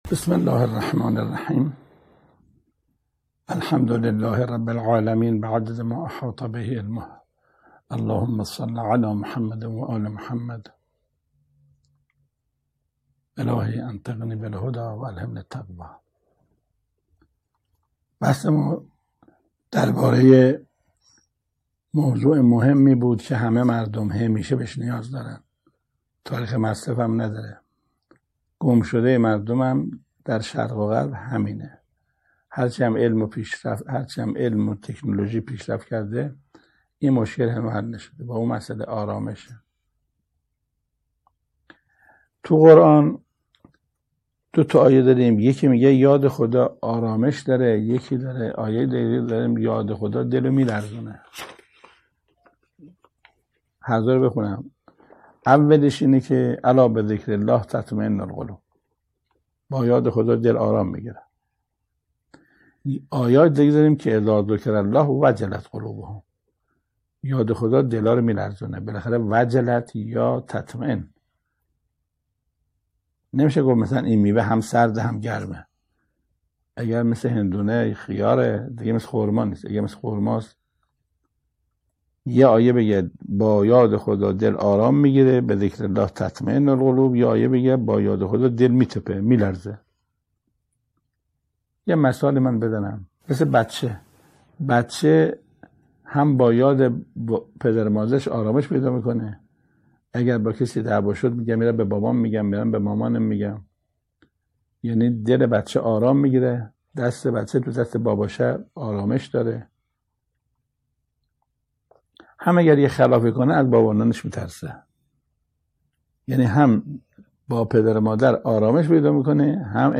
تلگرام واتس آپ توییتر فیسبوک 1374 بازدید چهار شنبه, 10 اردیبهشت 1399 منتخب سخنرانی بیشتر حجت الاسلام قرائتی بیشتر با یاد خدا دل ها آرامش می گیرد یا میلرزد؟